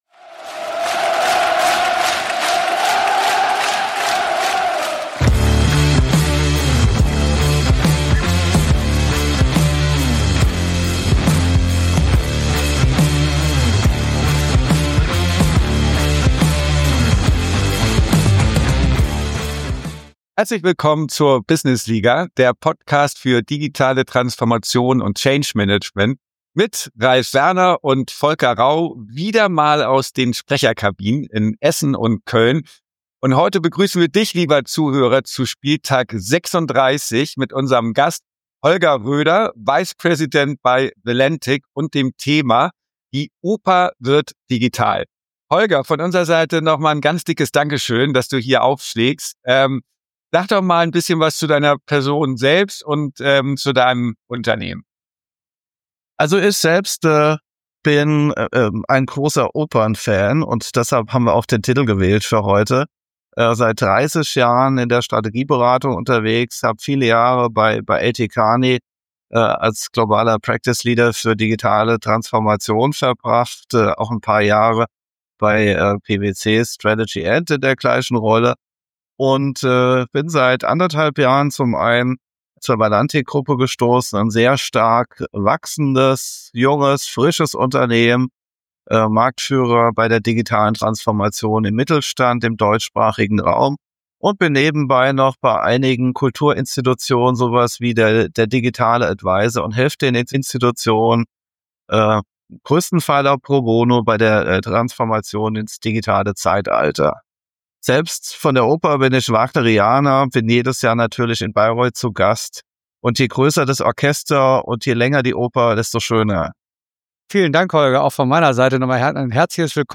In der Interviewzone konnten wir ihn aber abfangen und sprachen mit ihm über die digitale Transformation in der Oper.